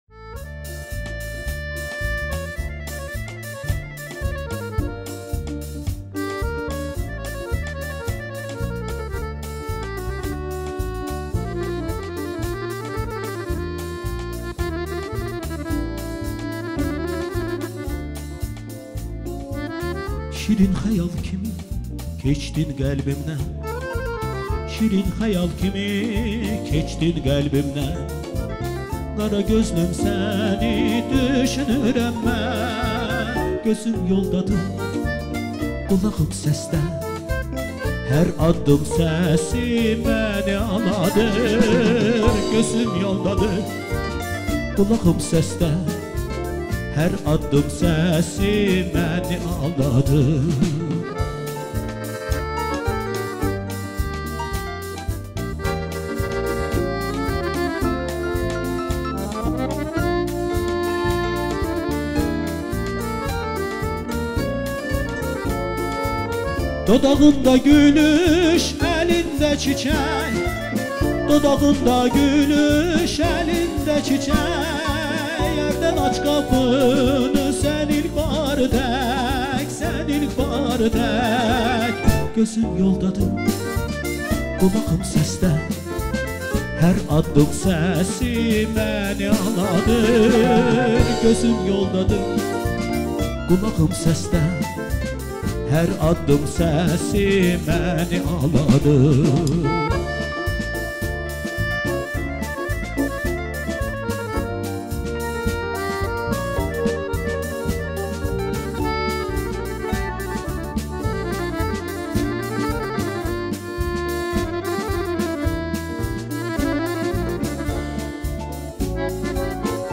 CANLI İFA